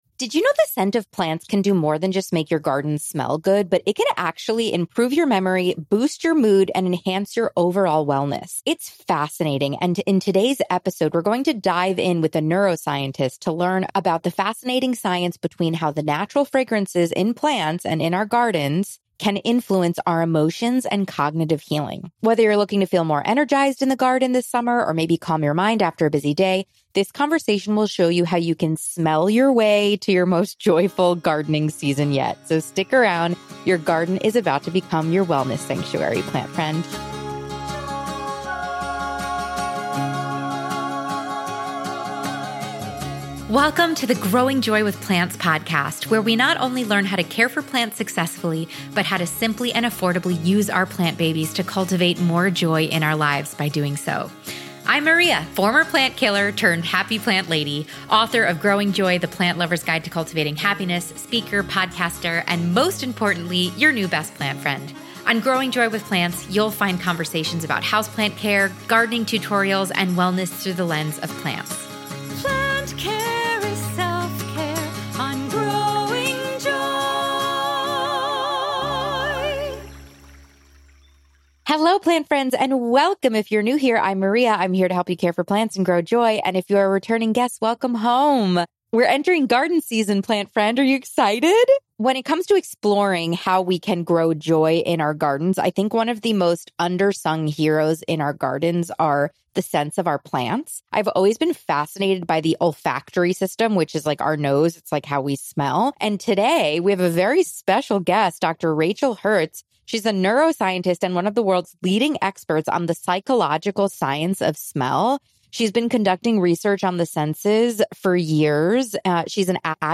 Mentioned in our conversation